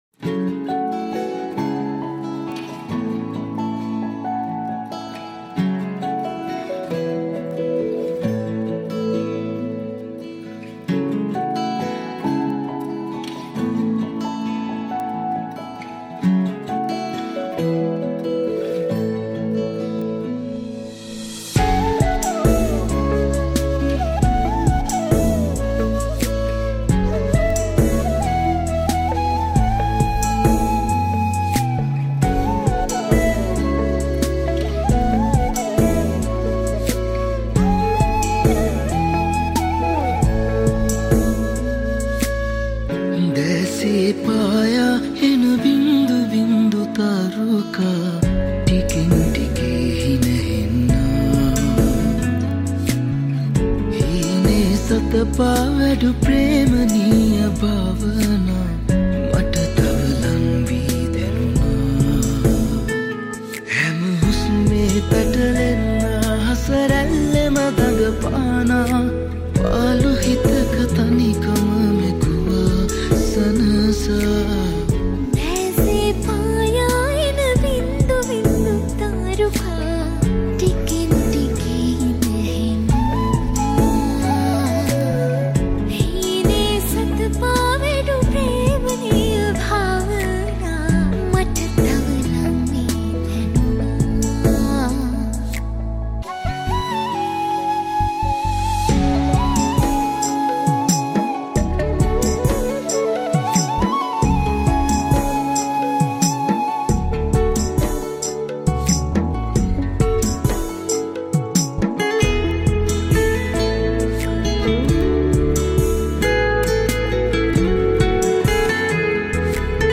Guitar
Flute